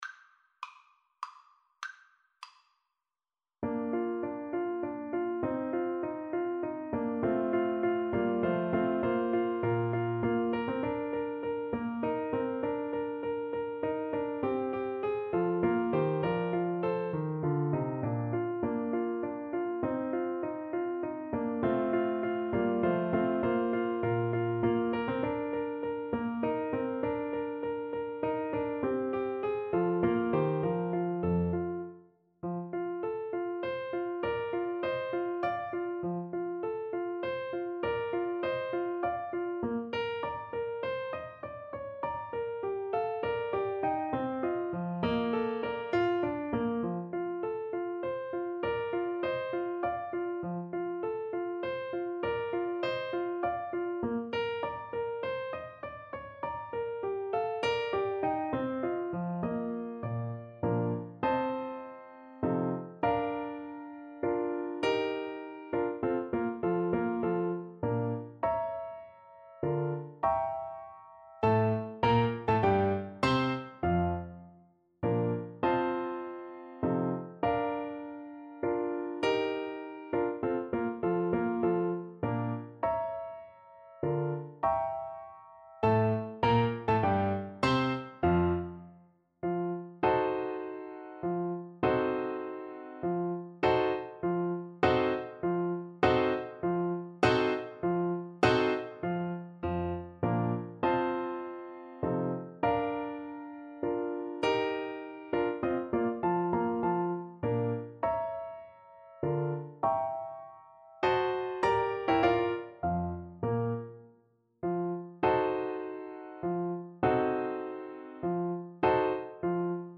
3/4 (View more 3/4 Music)
Menuetto Moderato e grazioso
Classical (View more Classical French Horn Music)